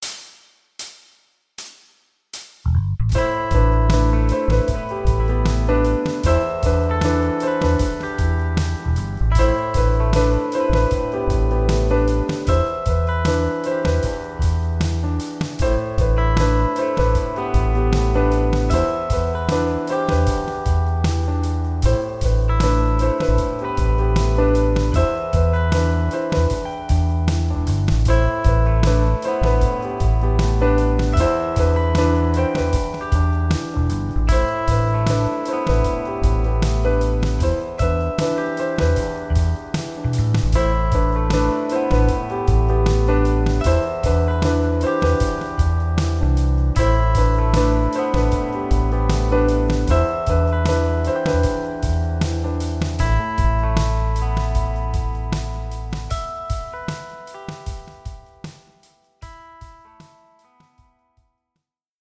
Tonalidade: la dórico; Compás: 4/4